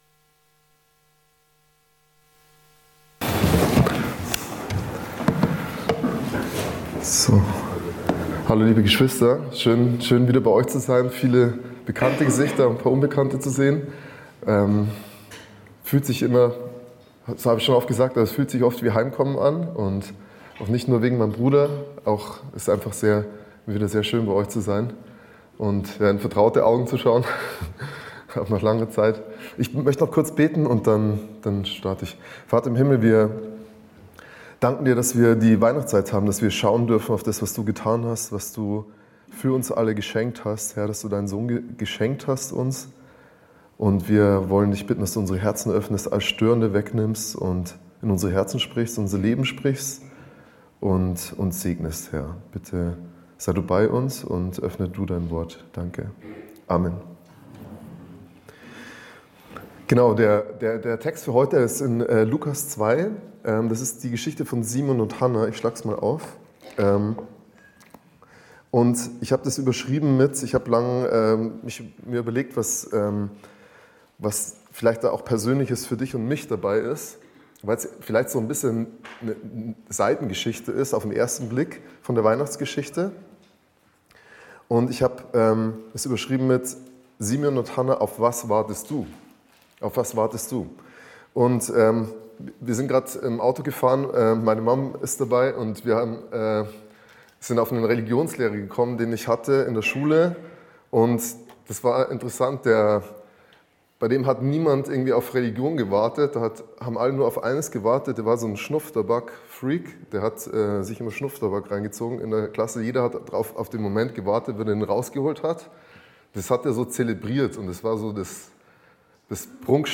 Predigtreihe